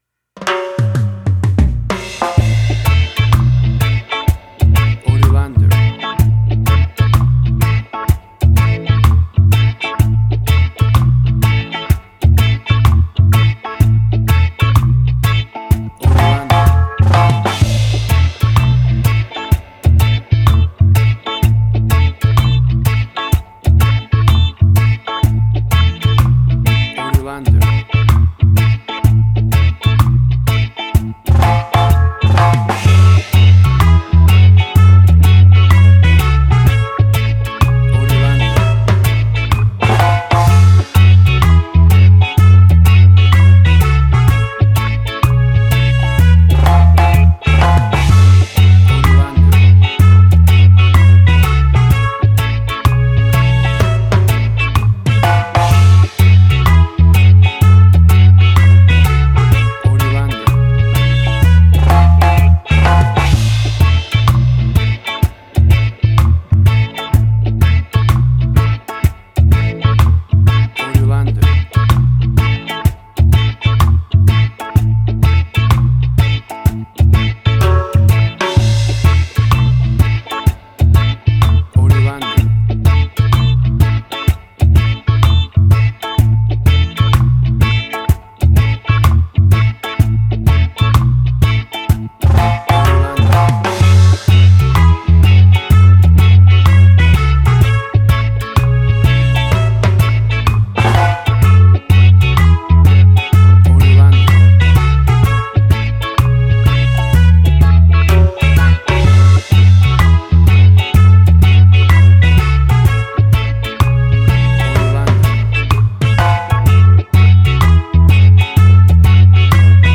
Classic reggae music with that skank bounce reggae feeling.
WAV Sample Rate: 16-Bit stereo, 44.1 kHz
Tempo (BPM): 63